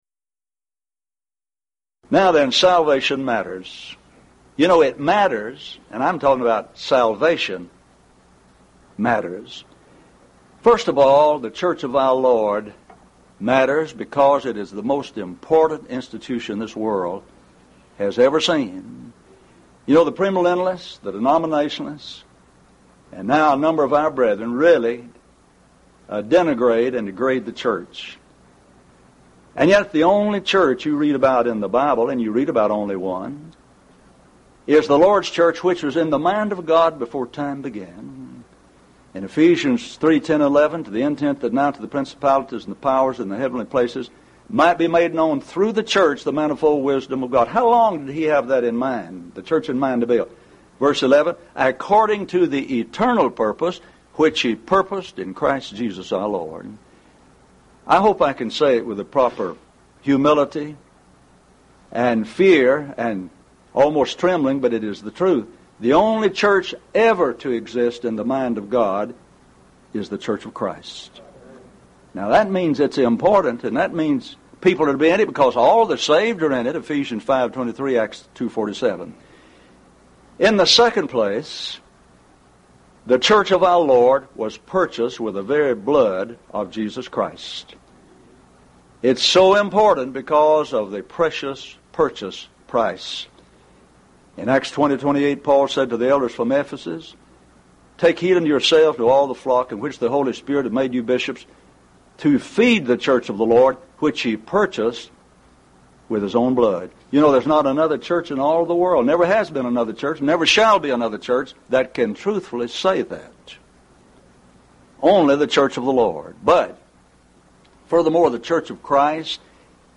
Event: 1st Annual Lubbock Lectures